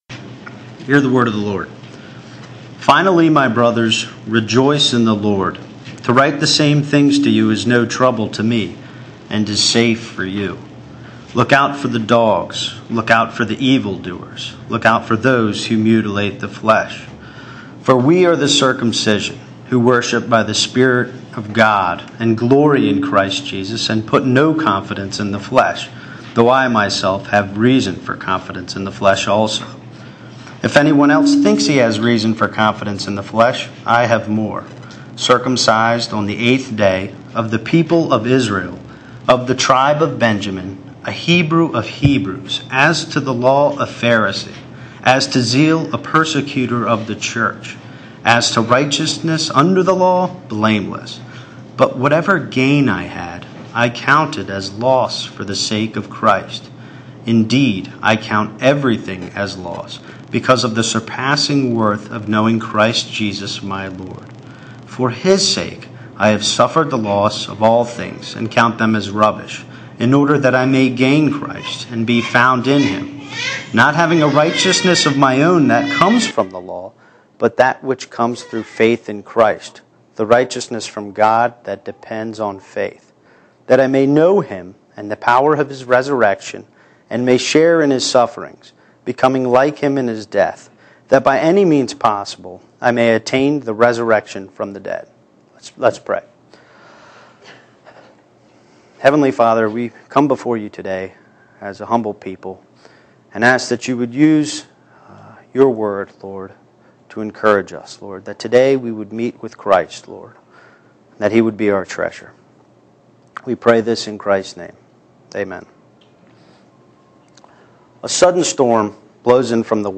All Sermons Rejoice in Suffering